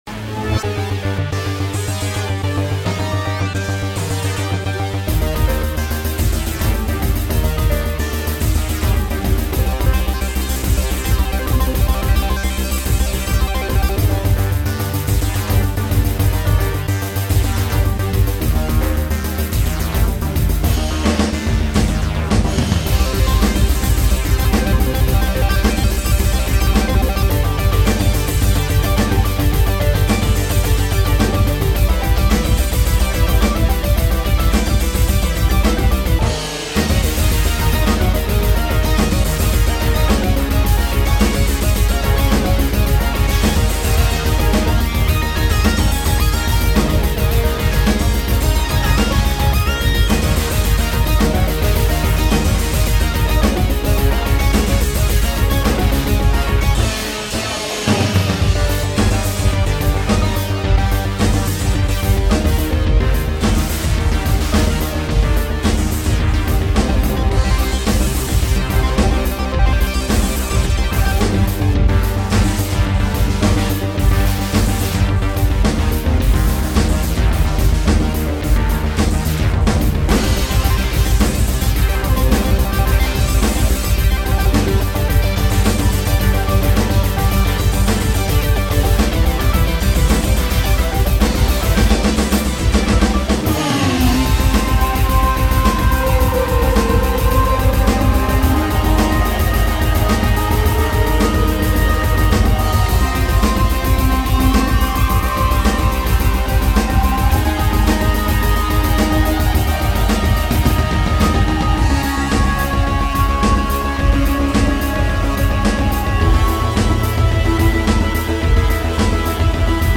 Roland Jp8000 + Spectral audio Protone